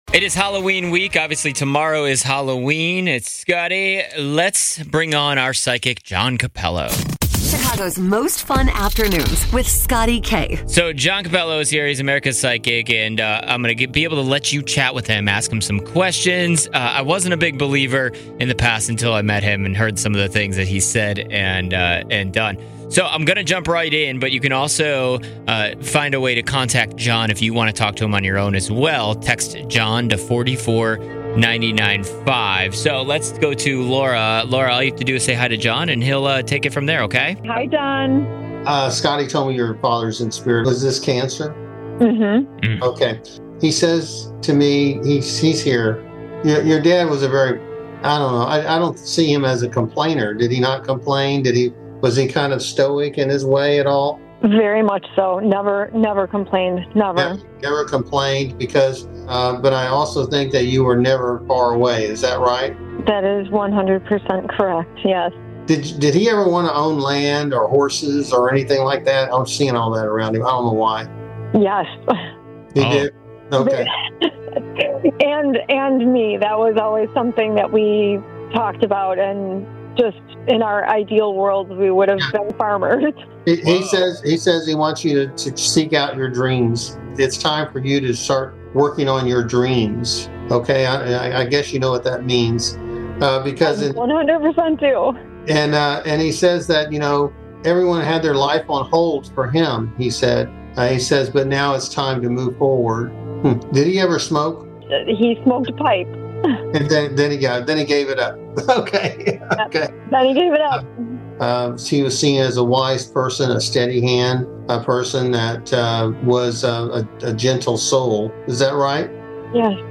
He is here to talk to you briefly on the air this week.